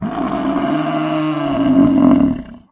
Звуки бизона
Громкий рык и носовое дыхание самца бизона